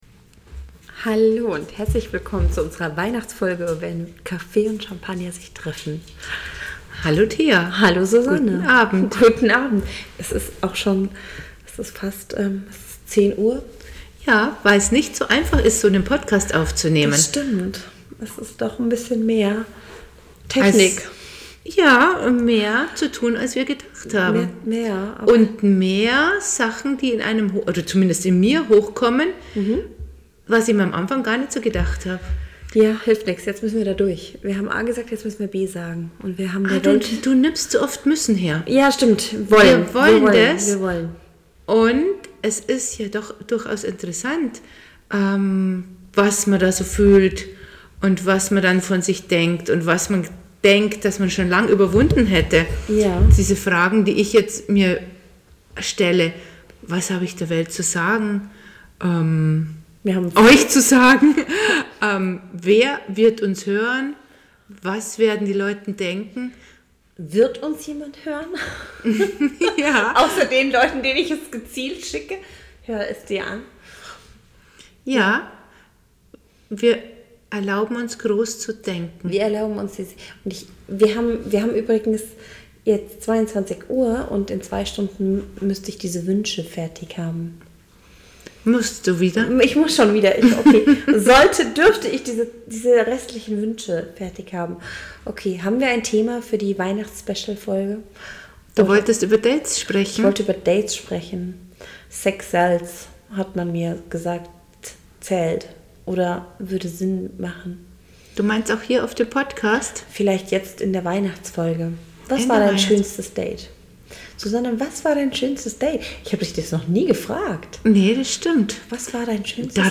Zwei Frauen die dich in ihre Gedanken mitnehmen.